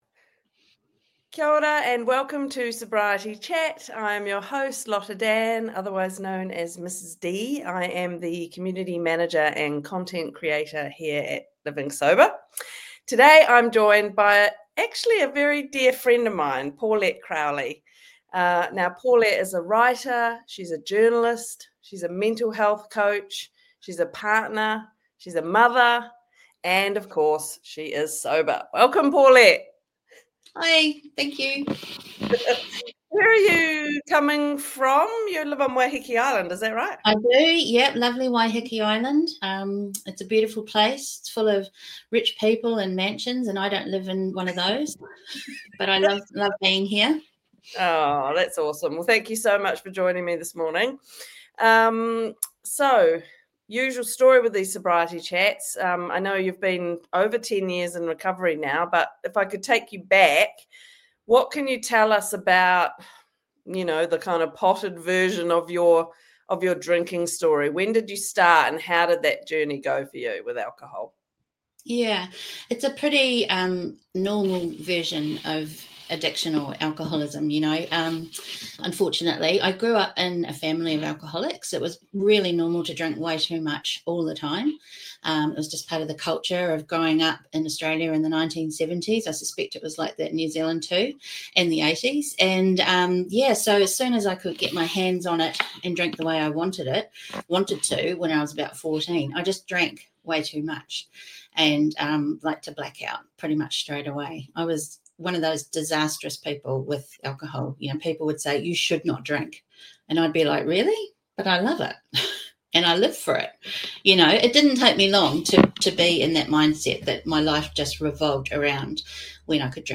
This conversation dives into my experience of fighting addiction while unknowingly navigating a neurodivergent brain.